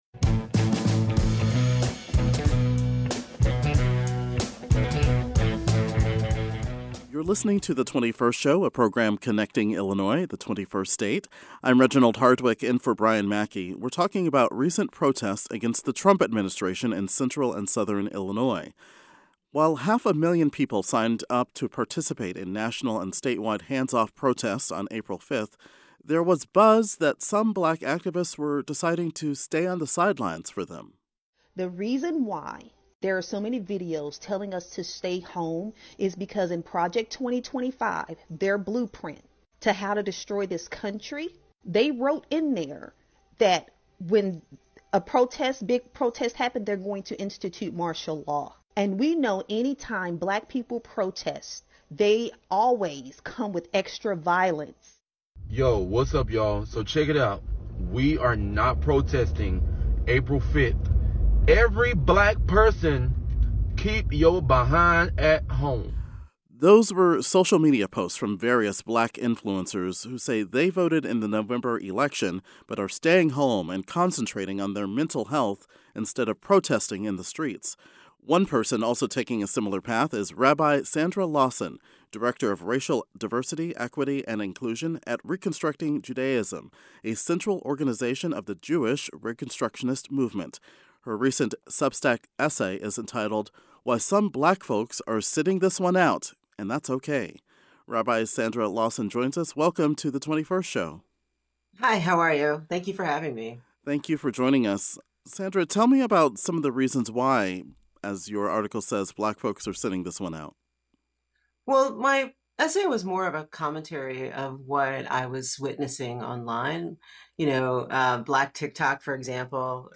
A Black activist, speaker, and author took a smiliar path and explains why on today's program.